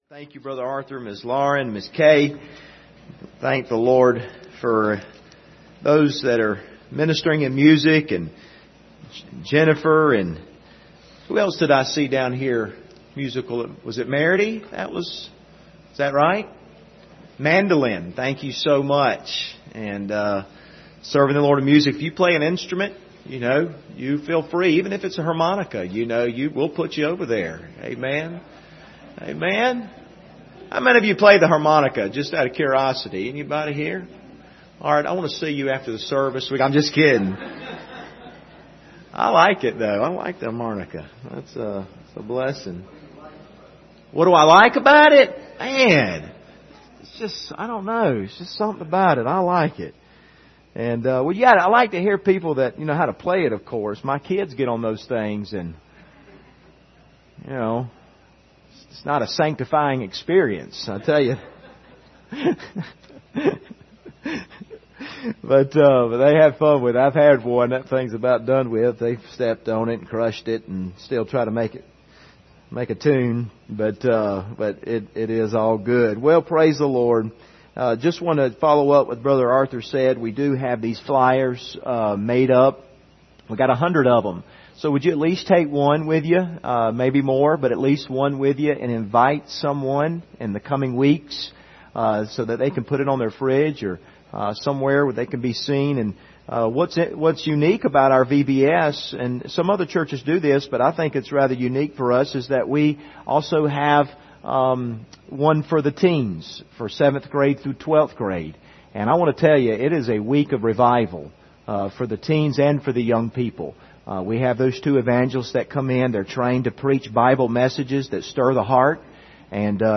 Service Type: Sunday Evening Topics: healing